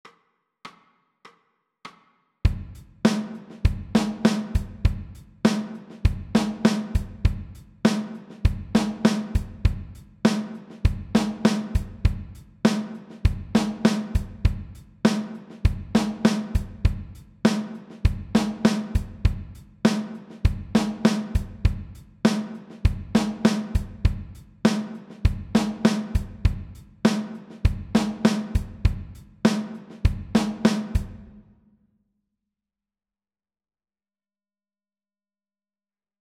TRANSCRIBING DRUM GROOVES
You will hear a 4 beat intro followed by a one bar drum groove repeated 12 times.